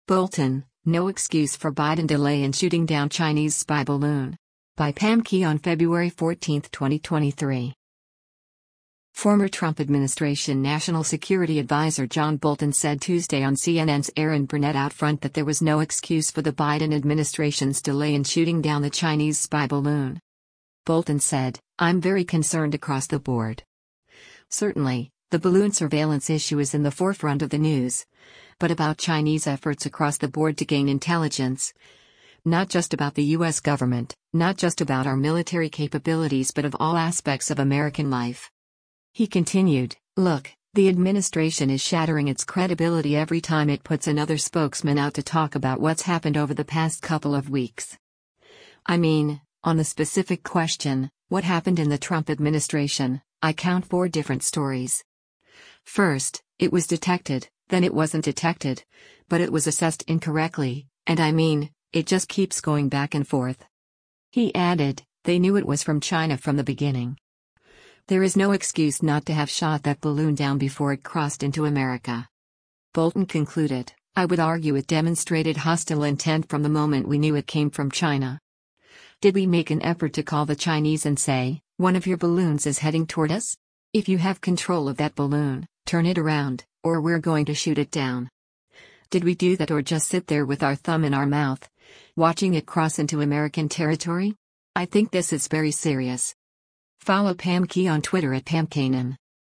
Former Trump administration National Security Adviser John Bolton said Tuesday on CNN’s “Erin Burnett OutFront” that there was “no excuse” for the Biden administration’s delay in shooting down the Chinese spy balloon.